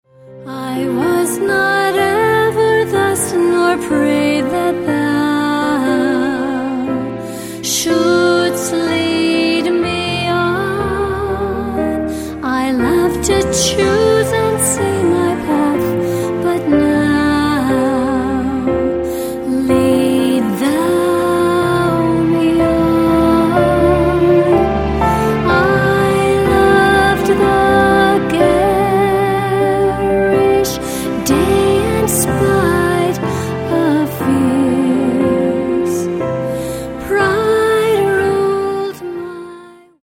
Piano - Strings - Low - Vocal